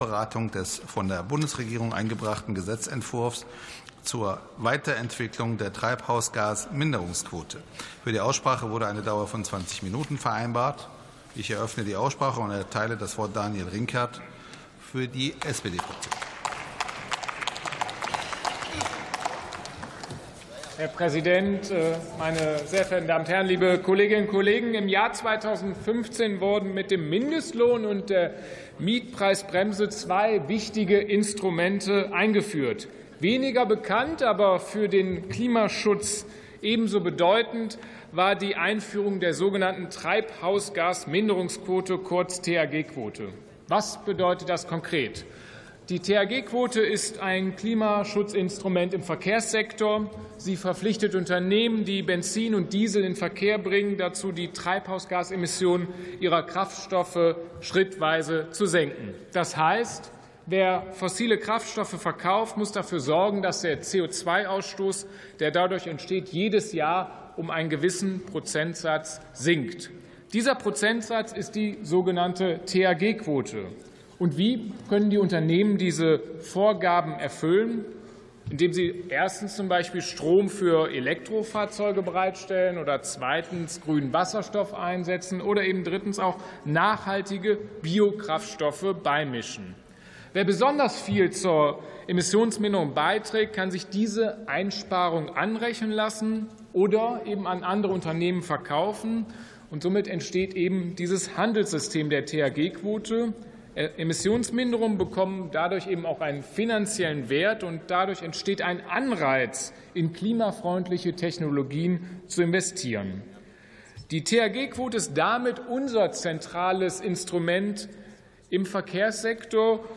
Sitzung vom 26.02.2026. TOP 3: Weiterentwicklung der Treibhausgasminderungs-Quote ~ Plenarsitzungen - Audio Podcasts Podcast